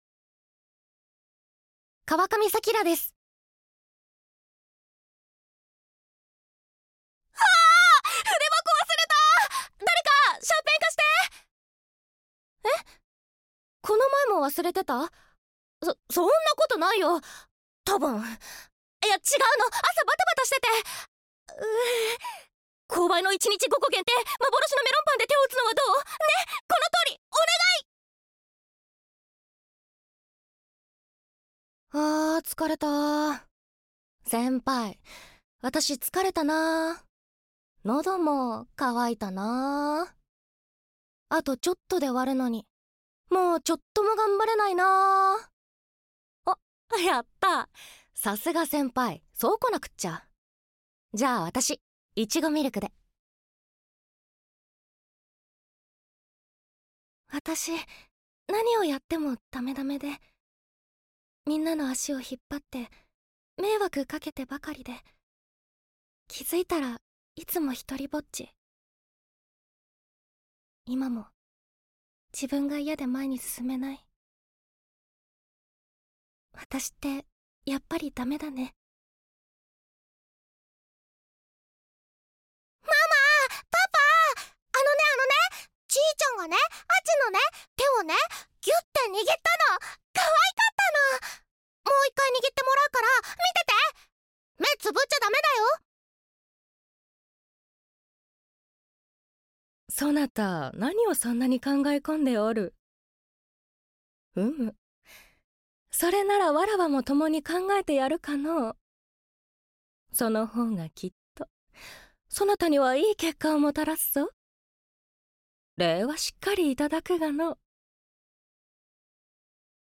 サンプルボイス